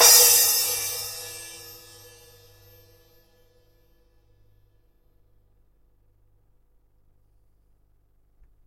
混合镲片 " Crash
我相信话筒是AKG 414。在录音室环境中录制的。
声道立体声